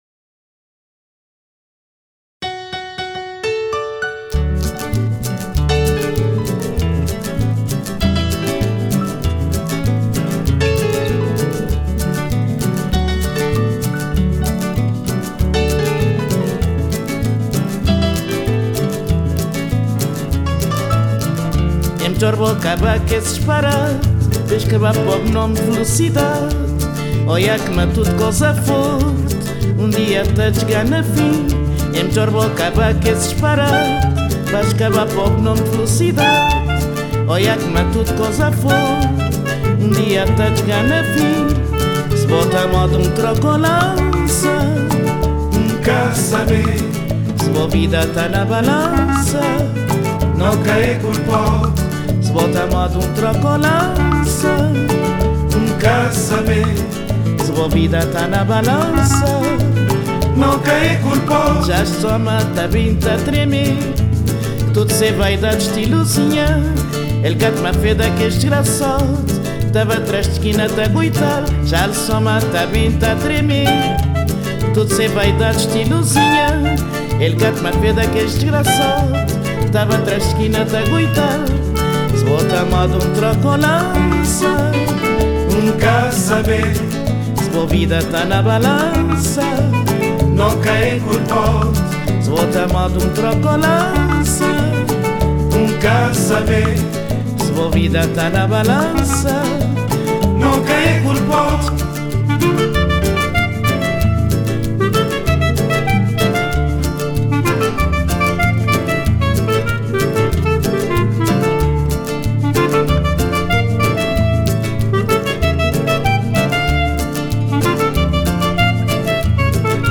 Под музыку, напоминающую смесь шансонных традиций и босановы